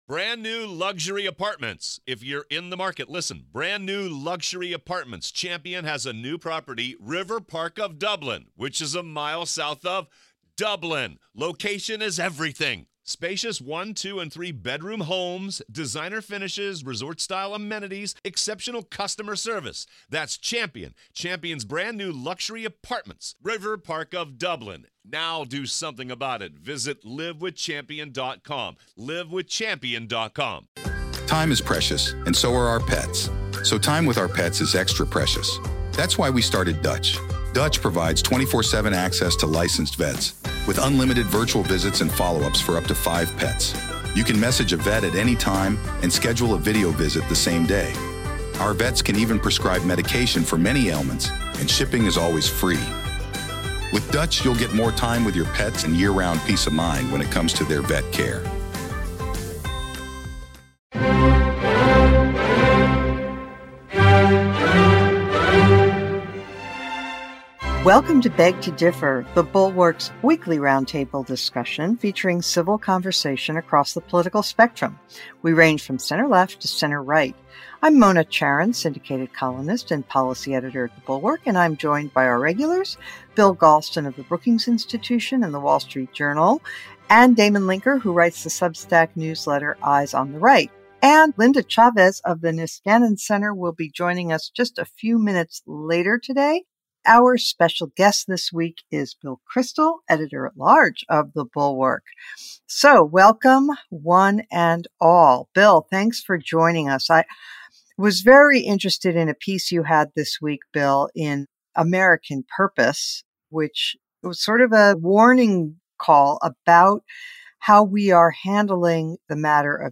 Bill Kristol joins the group to consider Haley's possible hidden strengths. Also, Biden, the GOP, and the war in Ukraine.